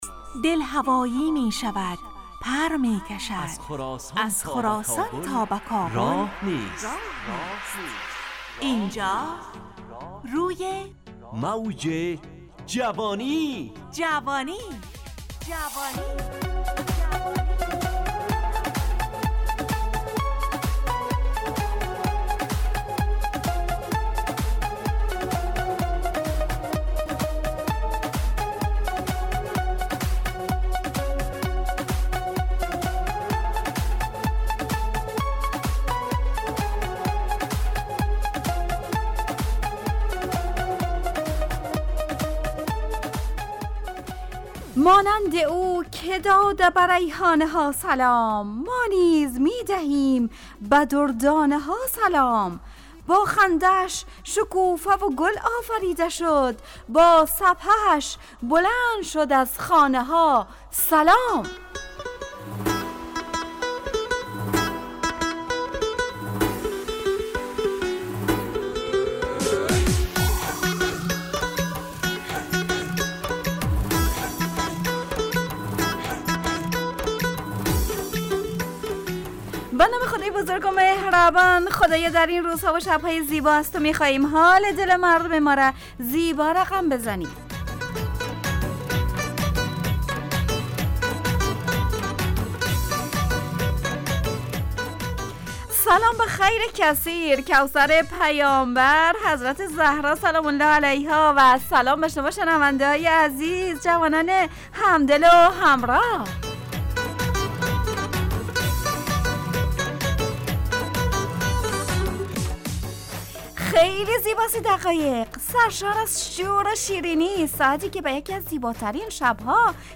روی موج جوانی، برنامه شادو عصرانه رادیودری.
همراه با ترانه و موسیقی مدت برنامه 55 دقیقه . بحث محوری این هفته (عیادت ) تهیه کننده